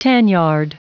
Prononciation du mot tanyard en anglais (fichier audio)
Prononciation du mot : tanyard